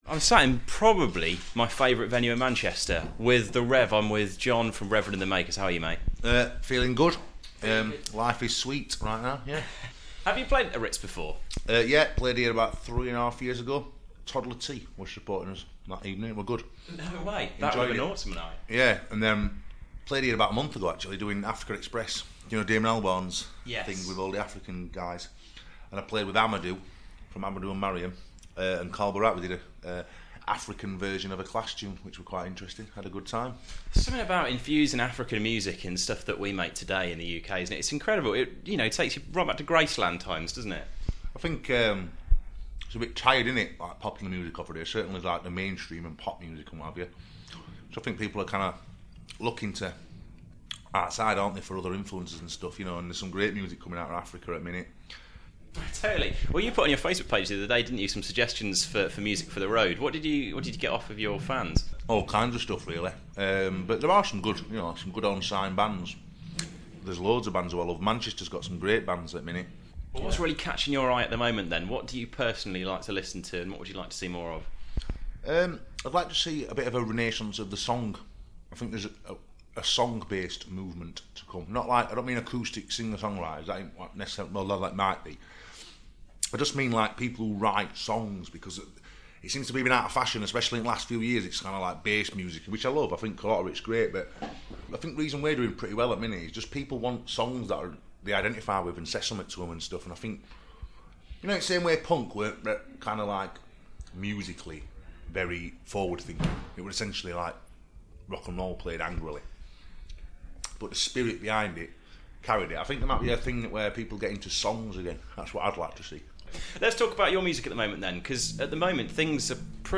A chat with John McClure from Reverend And The Makers at Manchester Ritz